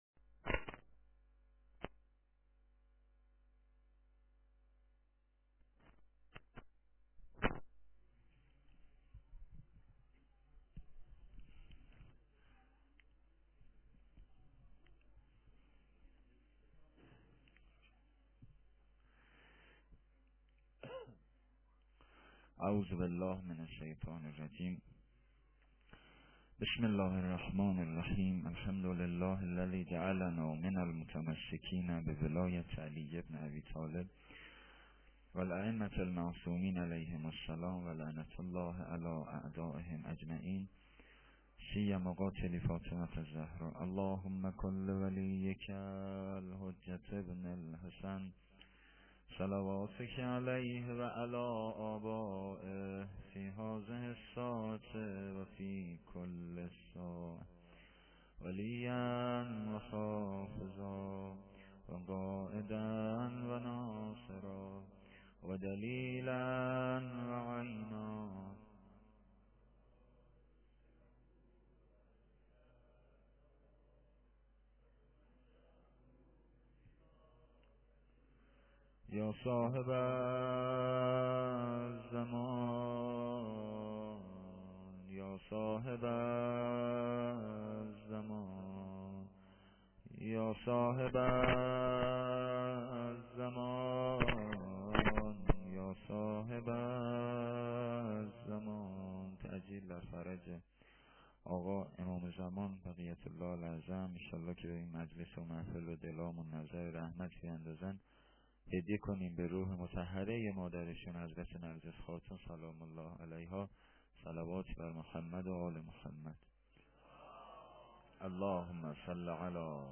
SOKHANRANI-6.lite.mp3